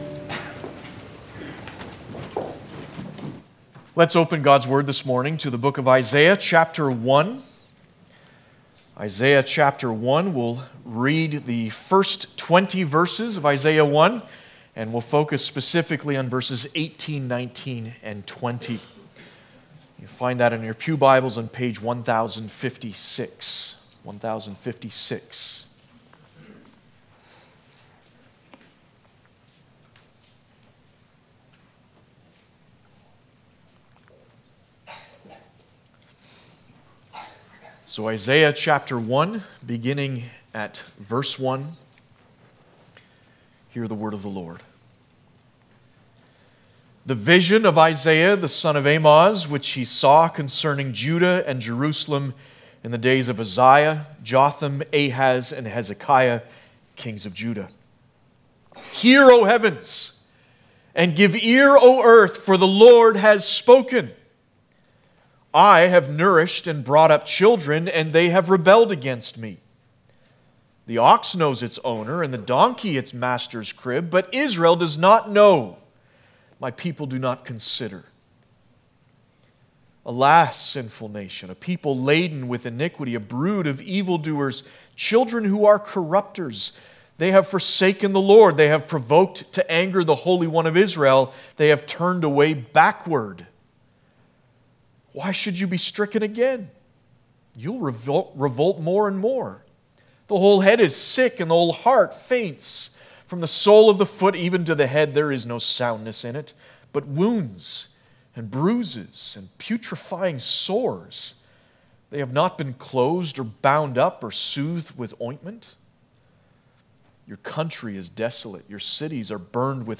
Passage: Isaiah 1:18-20 Service Type: Sunday Morning